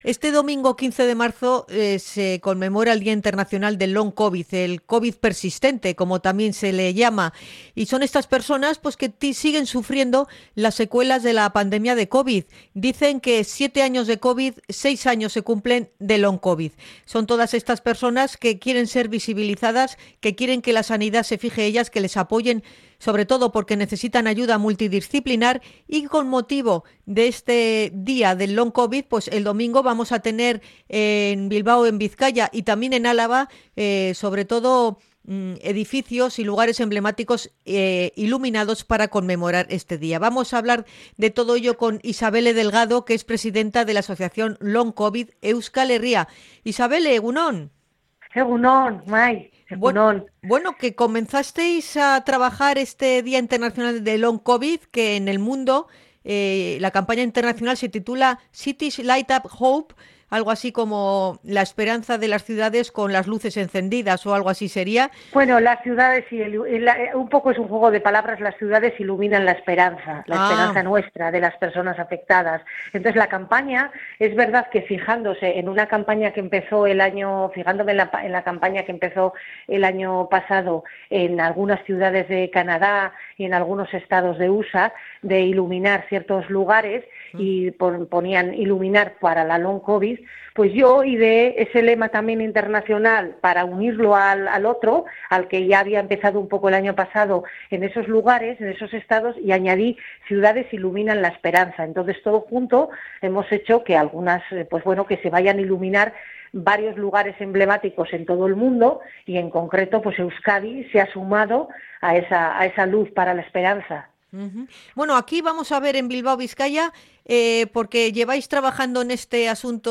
INT.-LONG-COVID-LIGHT-HOPE.mp3